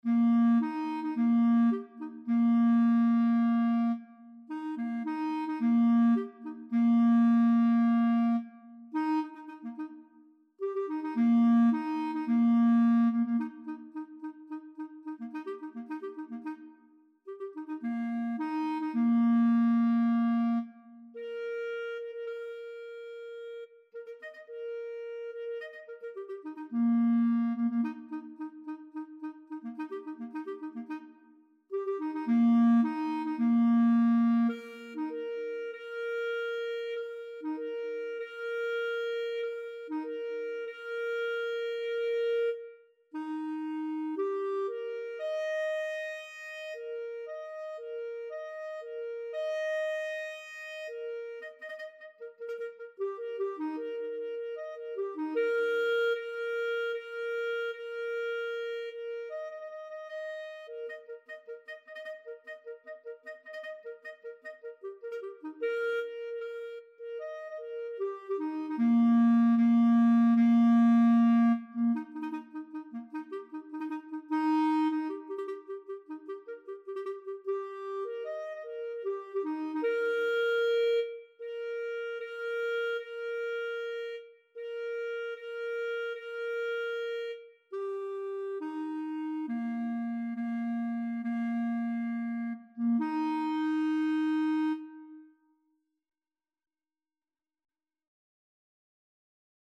2/4 (View more 2/4 Music)
Eb major (Sounding Pitch) F major (Clarinet in Bb) (View more Eb major Music for Clarinet )
Moderately fast ( = c.108)
Clarinet  (View more Easy Clarinet Music)
Classical (View more Classical Clarinet Music)